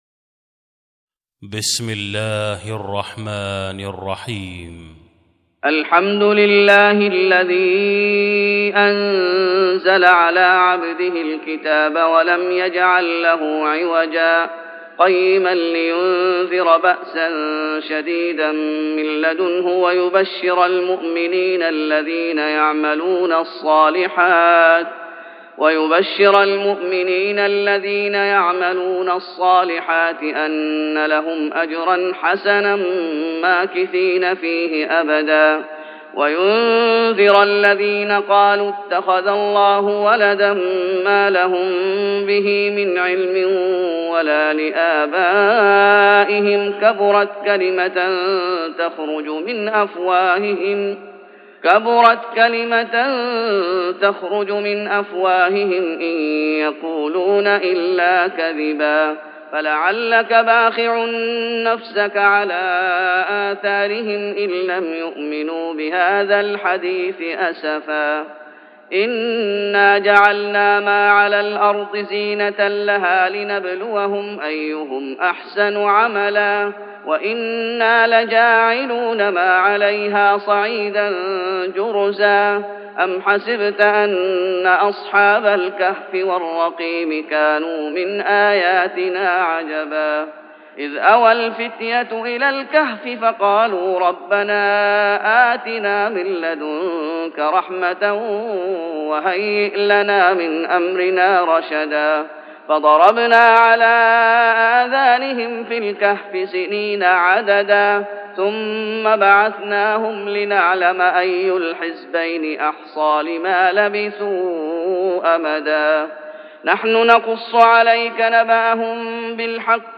تهجد رمضان 1416هـ من سورة الكهف (1-29) Tahajjud Ramadan 1416H from Surah Al-Kahf > تراويح الشيخ محمد أيوب بالنبوي 1416 🕌 > التراويح - تلاوات الحرمين